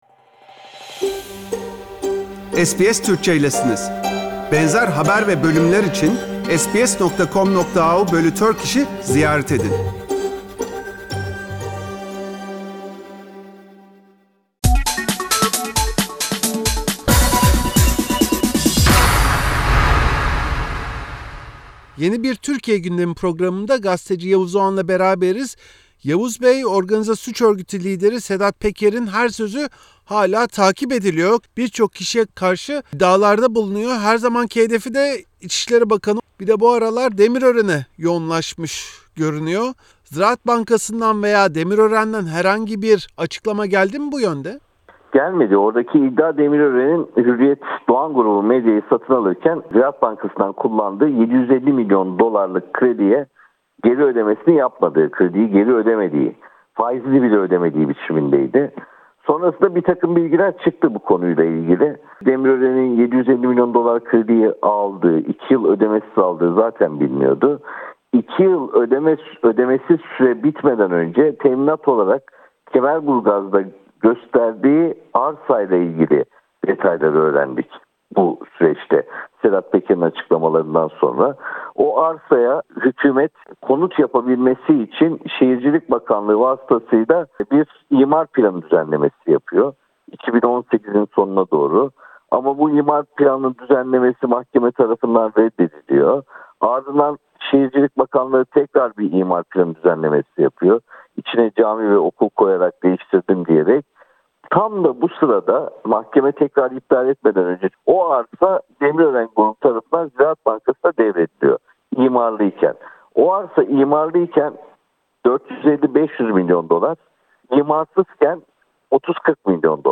Gazeteci